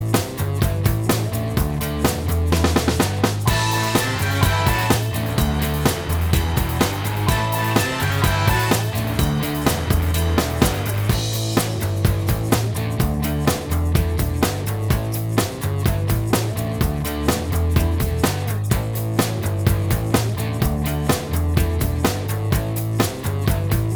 Minus All Guitars Rock 4:31 Buy £1.50